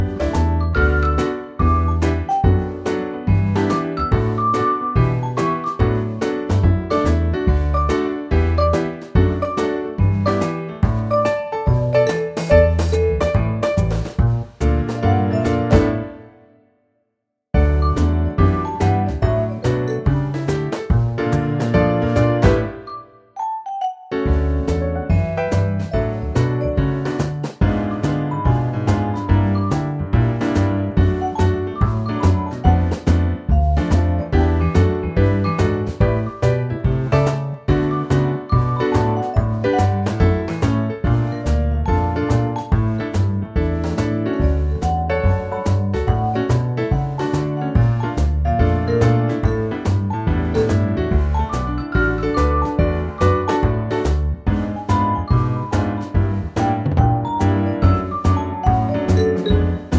Live Version Jazz / Swing 4:01 Buy £1.50